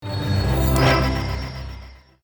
sound_open_vt.mp3